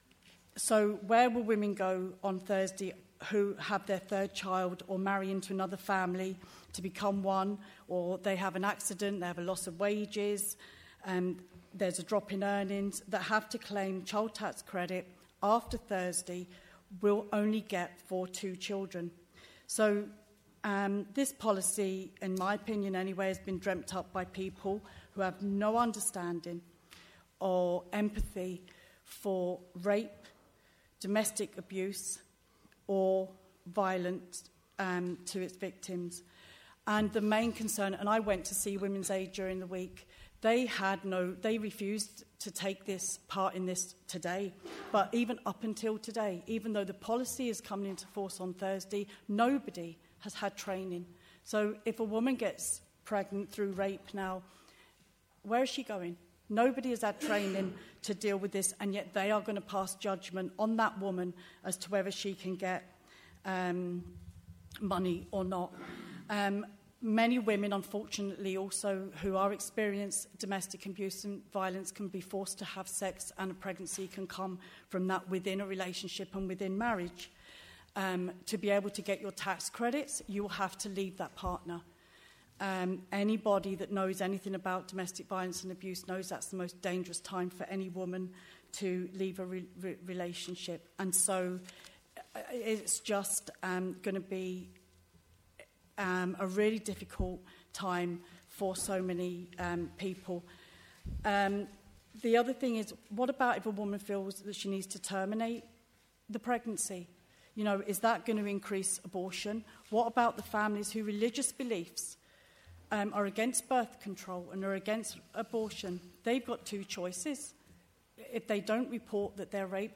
(Councillor Debbie Coyle's discussion at the council meeting is linked at the bottom of the page).